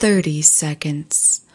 用USB麦克风和Audacity录制！
标签： 说话 说话 声音 女孩 英语 女性 讲话 美国 声乐 请求女人
声道立体声